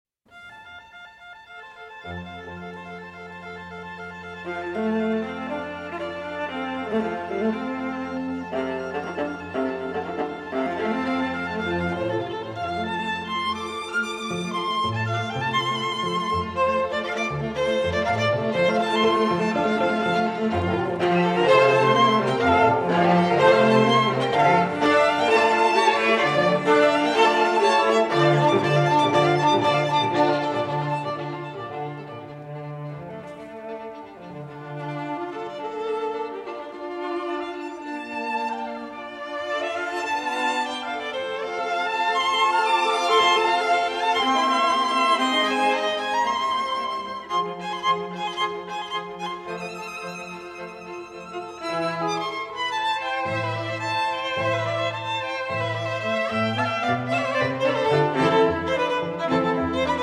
the string quartet